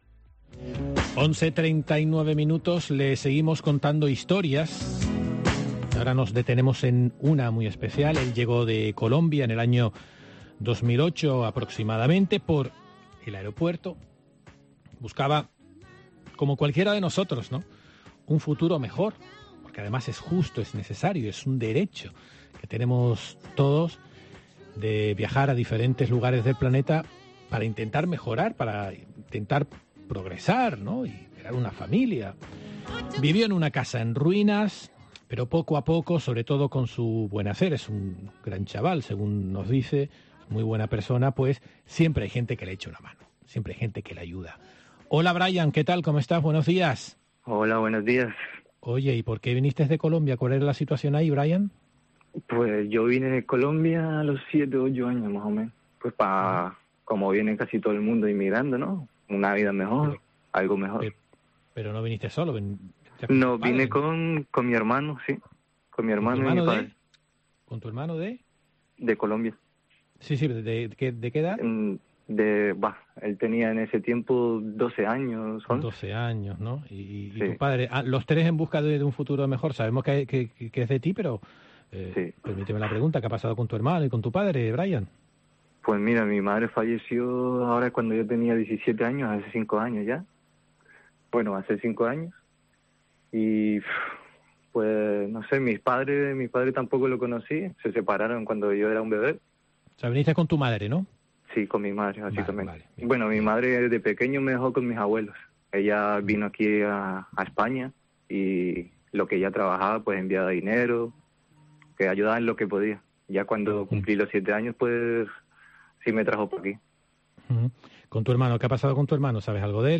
En los micrófonos de COPE Gran Canaria hemos escuchado el testimonio de este veinteañero que llegó desde Colombia junto con su hermano y su madre cuando tenía poco más de 7 años.